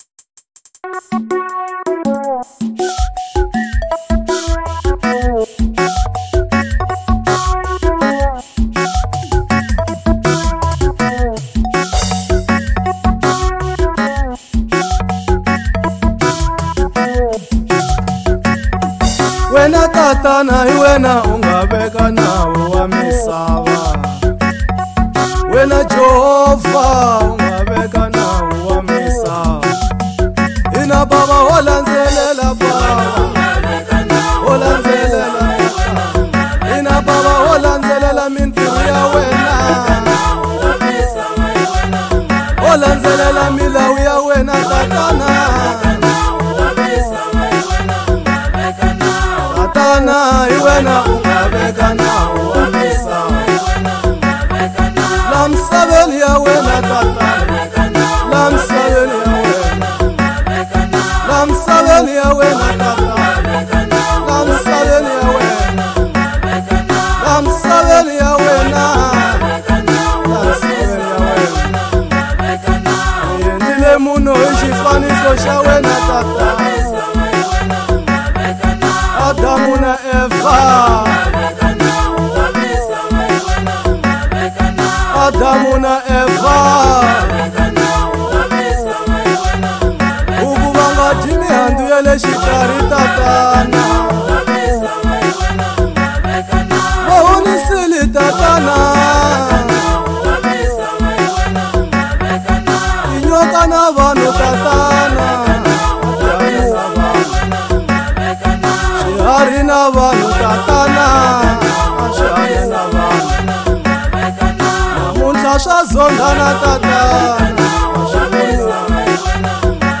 05:56 Genre : Gospel Size